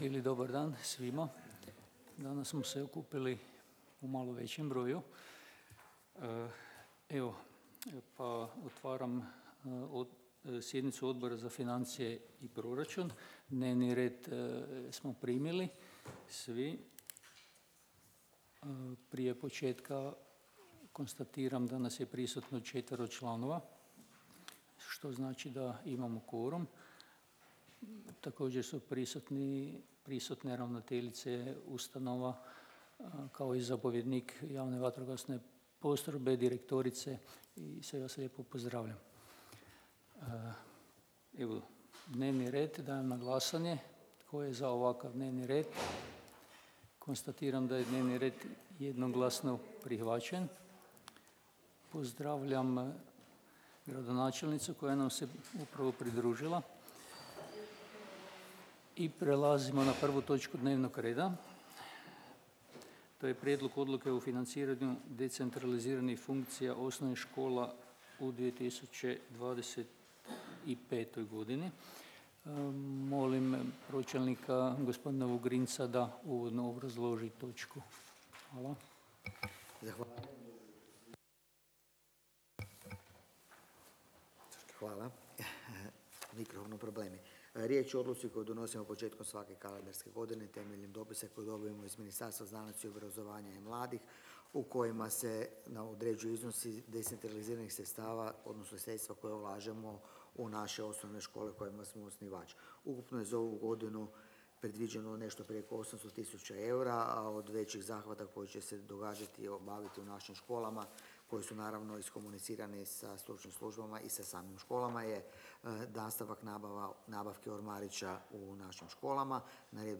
Obavještavam Vas da će se 24. sjednica Odbora za financije i proračun Gradskog vijeća Grada Čakovca održati dana 11. ožujka 2025. (utorak), u 08:30 sati, u gradskoj vijećnici Grada Čakovca.